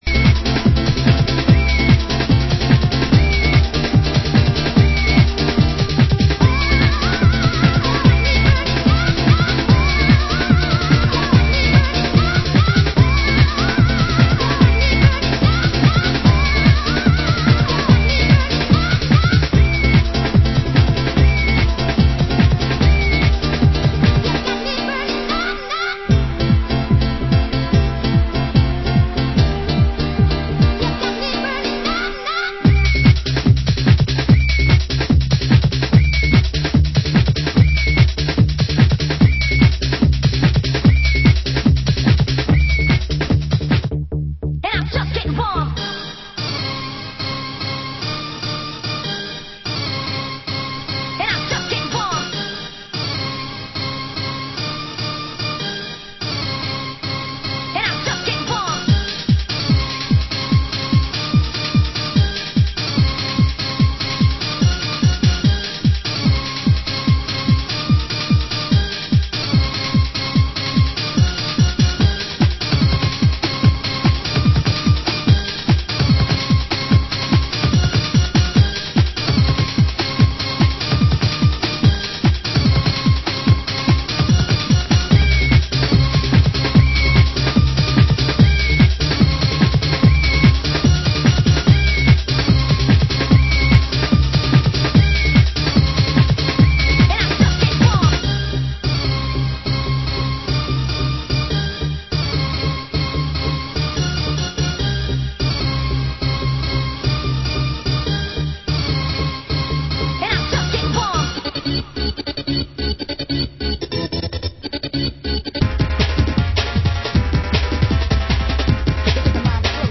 Genre: Minimal